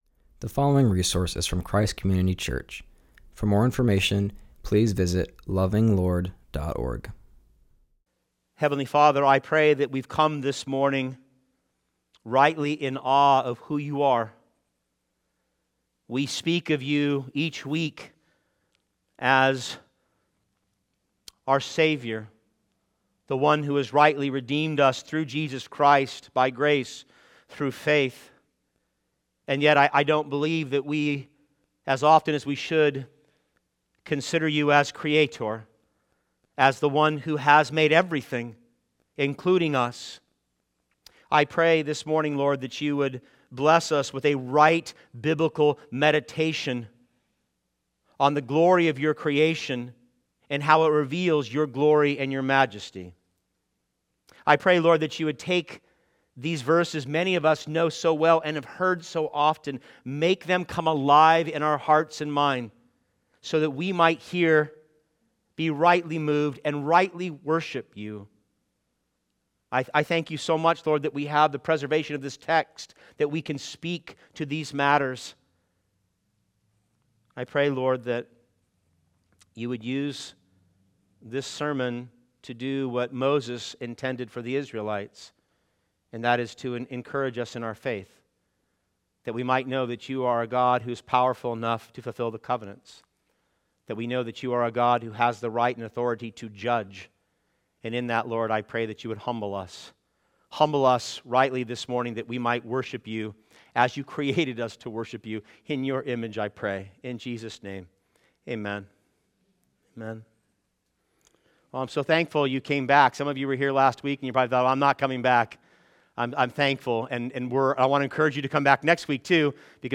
continues our series and preaches from Genesis 1:3-27.